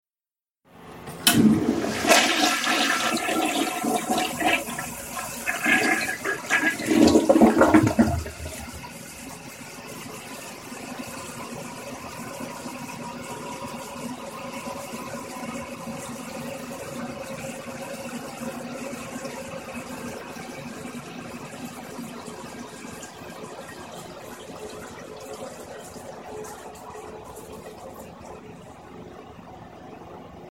Download Toilet Flush sound effect for free.
Toilet Flush